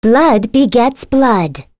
Lewsia_A-Vox_Skill1_c.wav